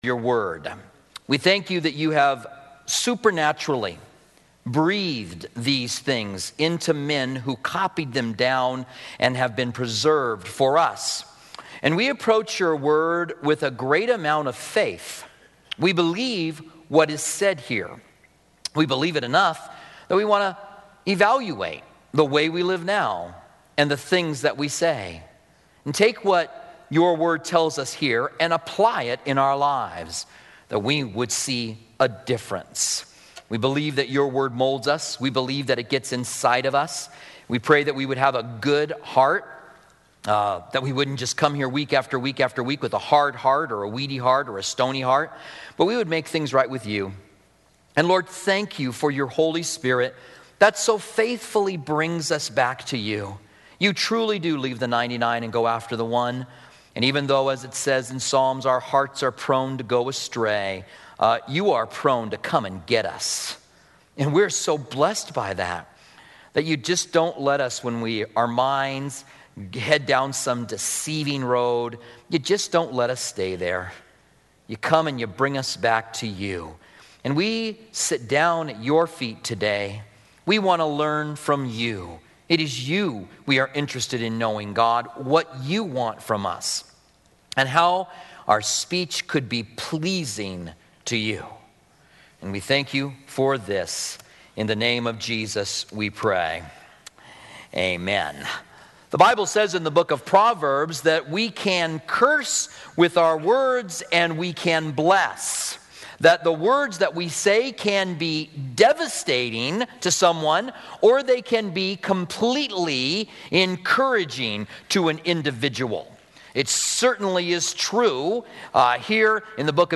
Commentary On James